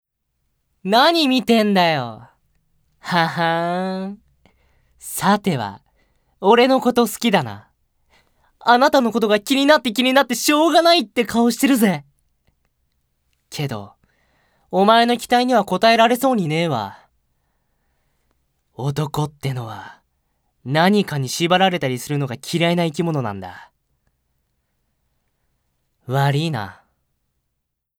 高音出ます。
ボイスサンプル、その他
セリフ２